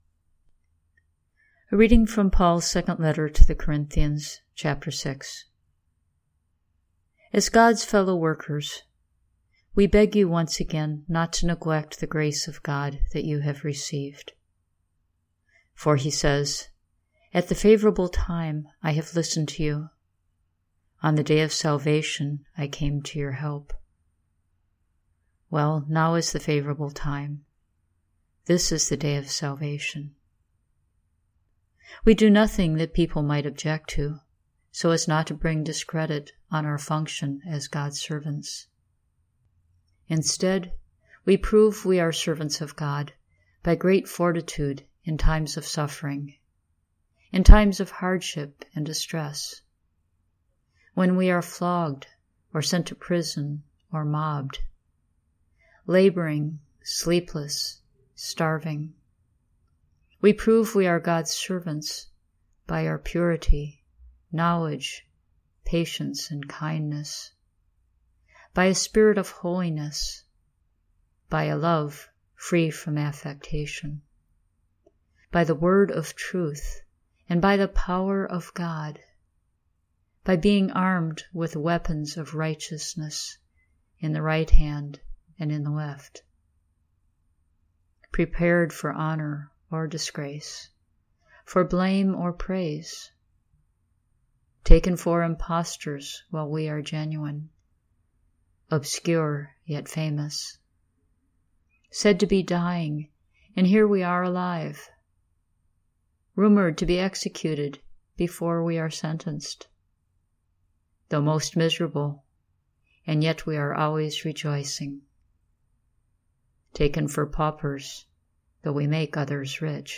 Let me share with you a recorded reading from Paul’s second letter to the Corinthians, today’s Scripture from the Roman calendar of readings.